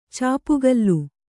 ♪ cāpugallu